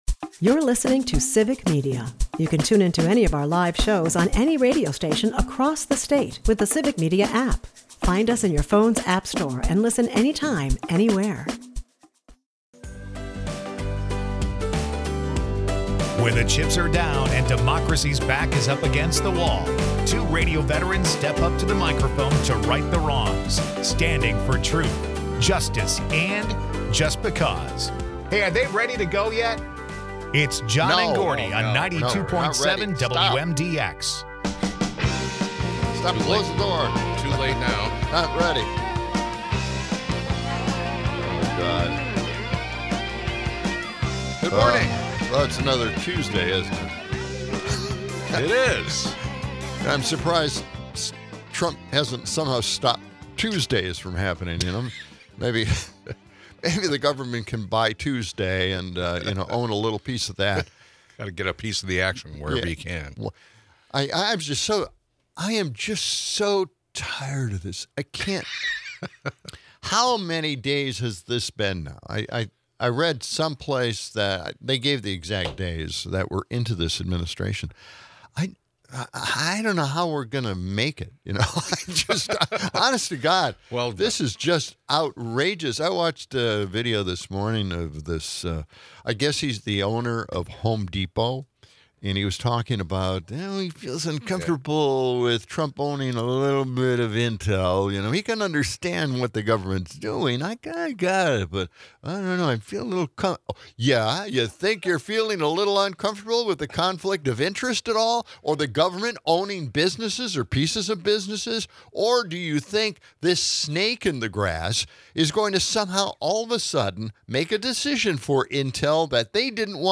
The episode fuses political satire with light-hearted segments, making for a punchy, engaging listen.